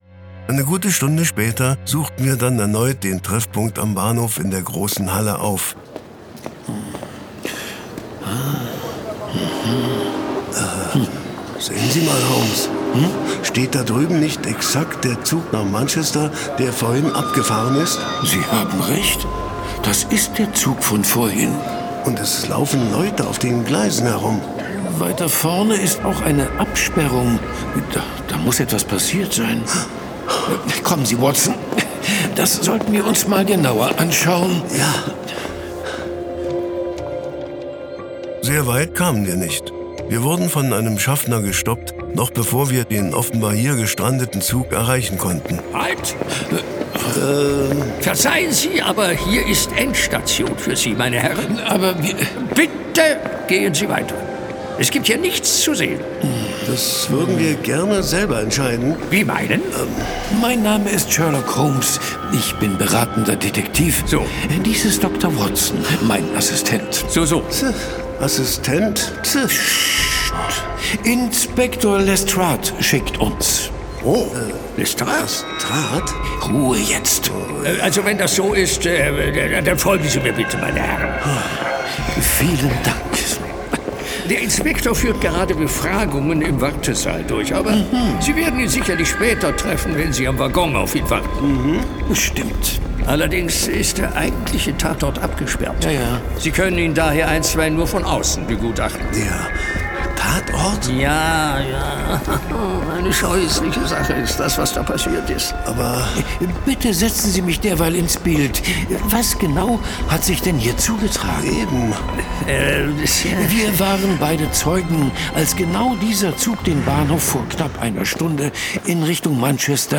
Hörspiel
Atmosphärische Krimi-Unterhaltung.